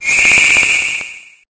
0b334fe3ea088a90ad4878bf950ece0abdf0f617 infinitefusion-e18 / Audio / SE / Cries / BLIPBUG.ogg infinitefusion 57165b6cbf 6.0 release 2023-11-12 15:37:12 -05:00 10 KiB Raw History